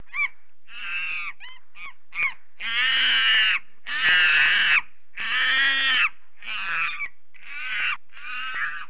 Monkey:
monkey.mp3